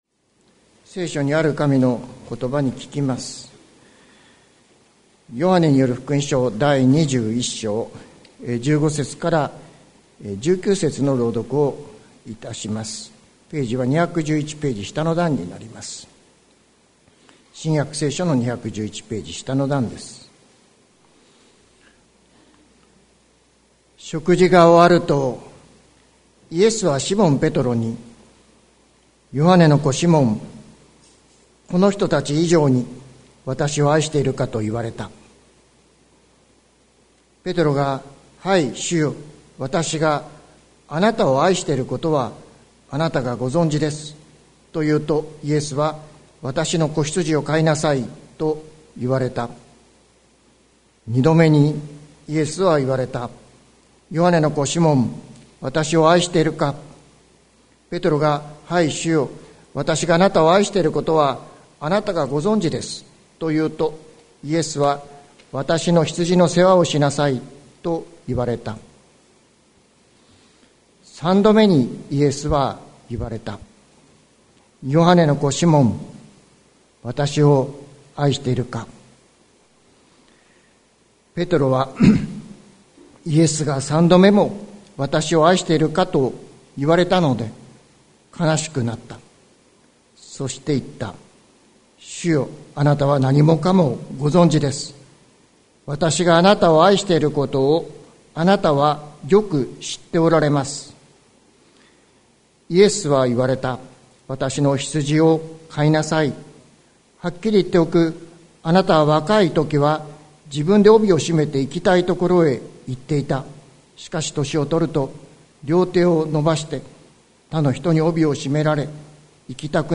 2024年03月24日朝の礼拝「これほど確かな愛はない」関キリスト教会
説教アーカイブ。